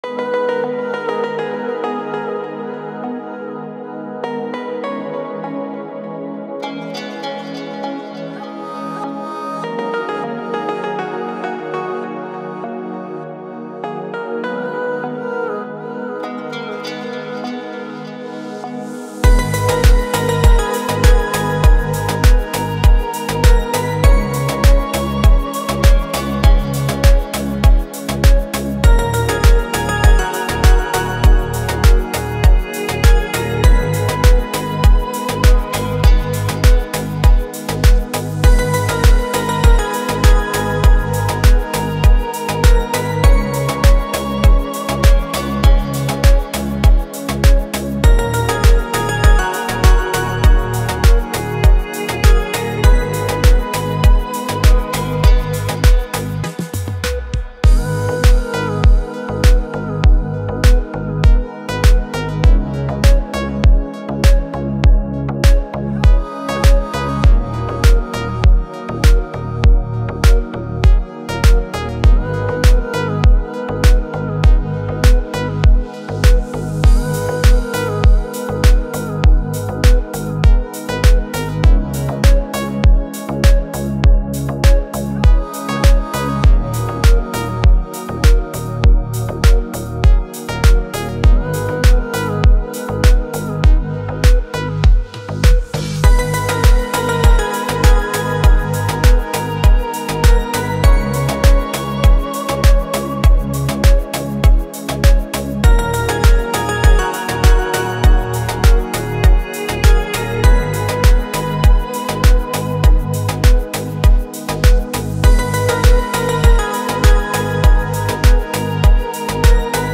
который отлично сочетает элементы поп и R&B